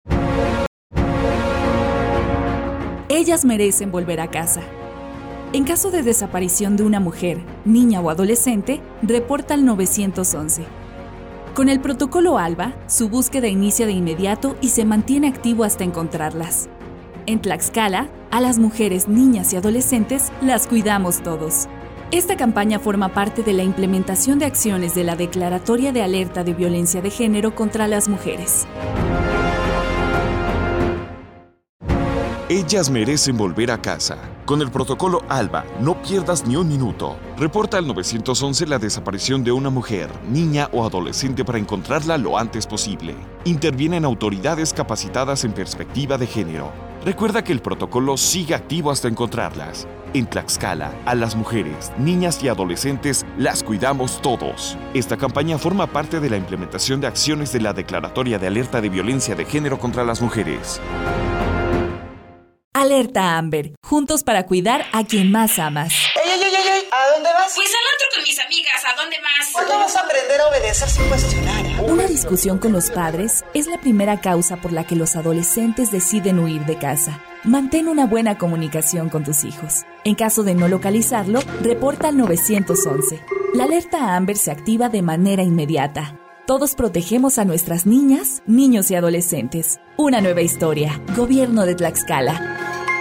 Spots de audio
SPOT ALBA FISCALÍA BÚSQUEDA ALL.mp3